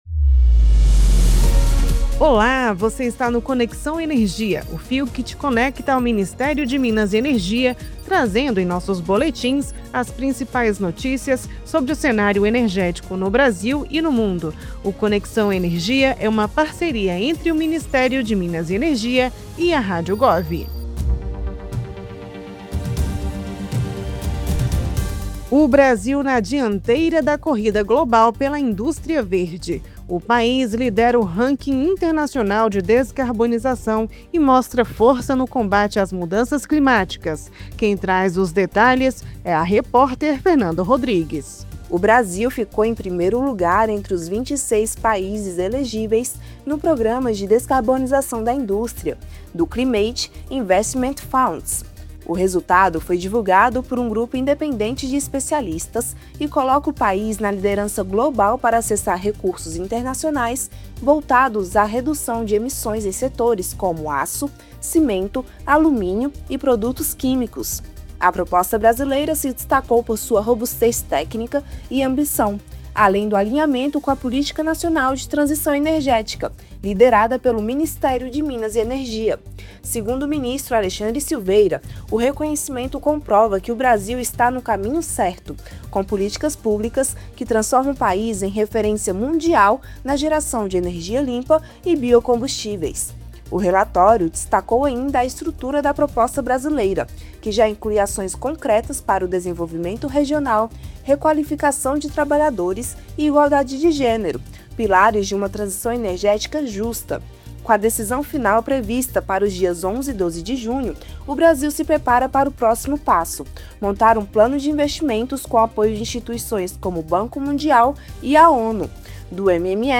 Boletim produzido pela assessoria de comunicação do Ministério de Minas e Energia com as atualizações do setor.